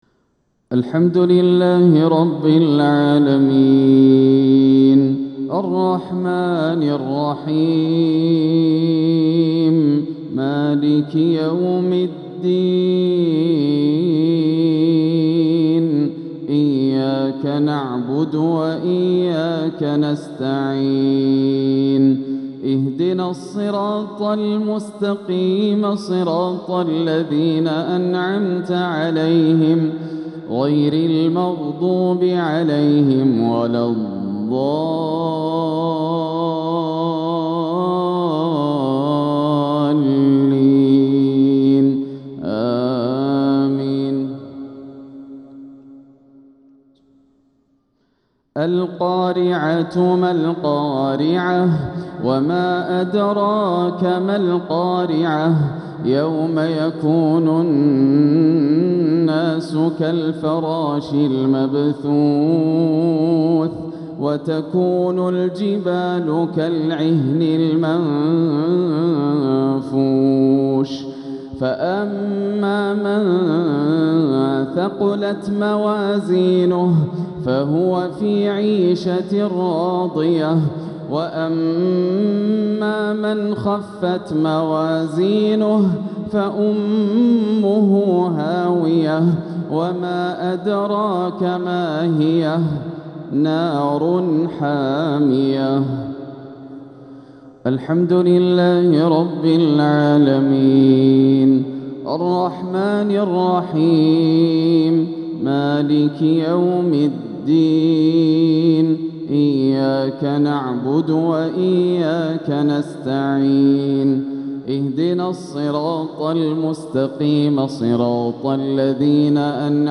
تلاوة لسورتي القارعة والعصر مغرب الأربعاء 5-2-1447 > عام 1447 > الفروض - تلاوات ياسر الدوسري